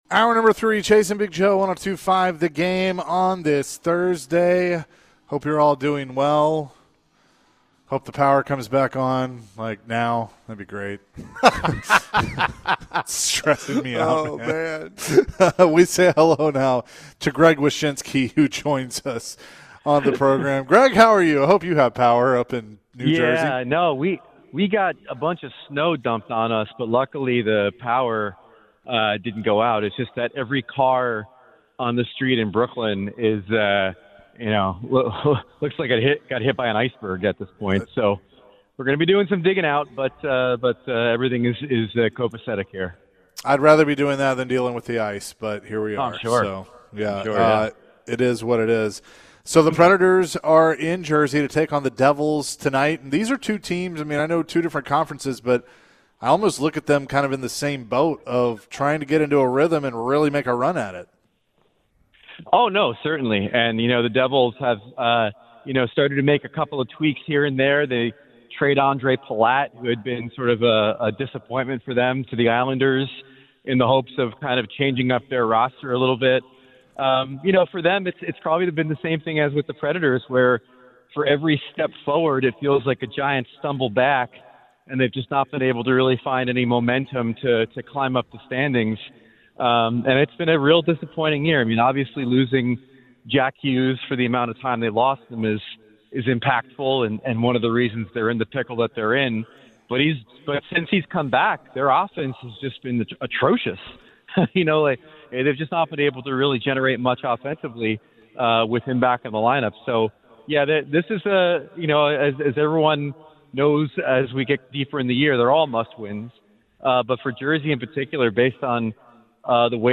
ESPN NHL Senior Writer Greg Wyshynski joined the show and share dhis thoughts on the Nashville Predators. How important is Juuse Saros to the Preds team? How can the defense improve under Andrew Brunette?